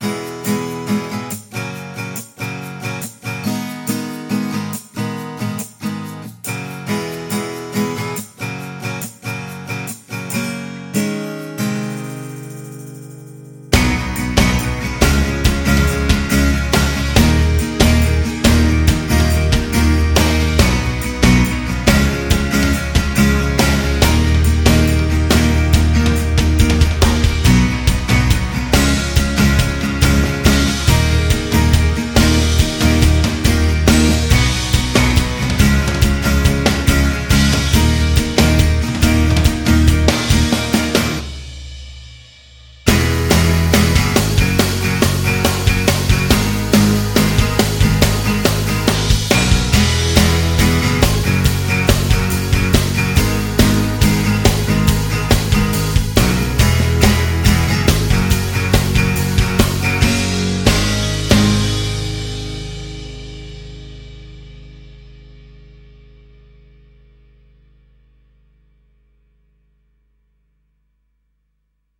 扫弦吉他 Splash Sound Guitar Strum KONTAKT-音频fun
- 四种弹奏类型：下弦，上弦，掌音，弦音
- 额外的击打音：开放弦静音击打（下/上），弹拨，琴身击打（低/高）
- 四个滑动调节器混合器：三重压缩麦克风（中/侧声道），立体声动态麦克风，压电传感器